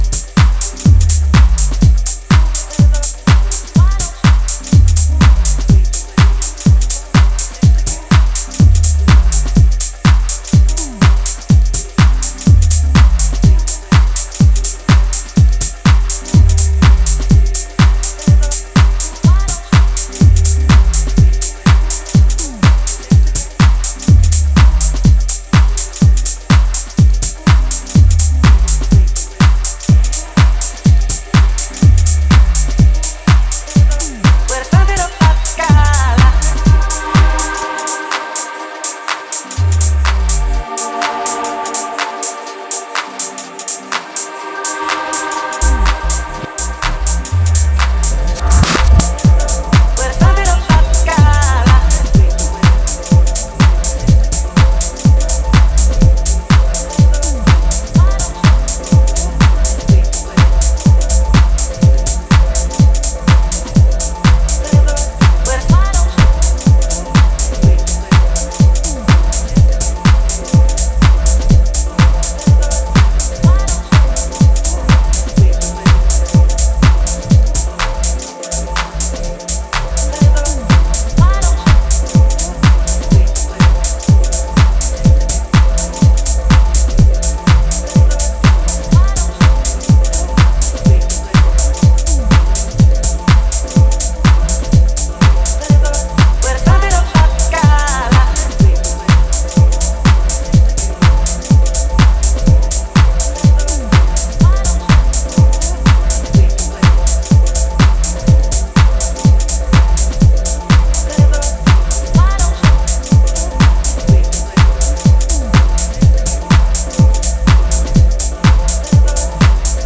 an excellent, driving twist
Boasting an exceptional sound thanks to mastering
Breaks
Deep house